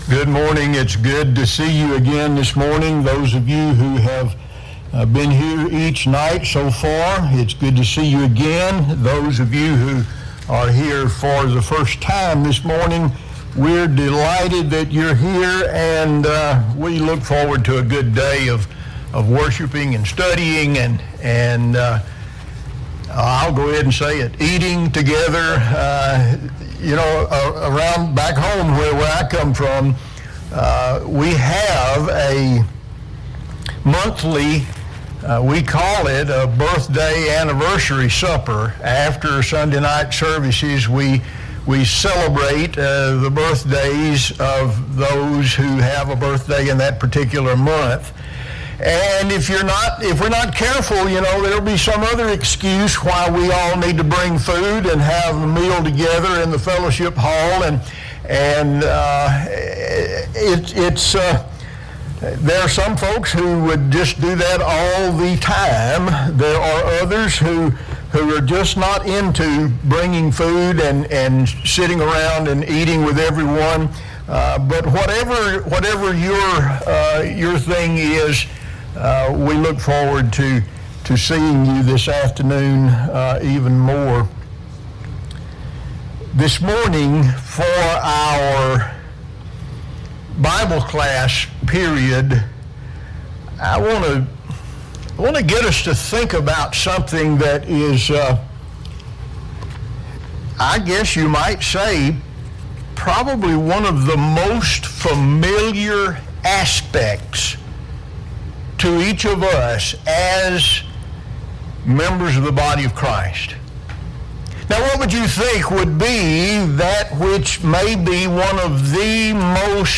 Gospel Meeting - Bible Class
Guest Speaker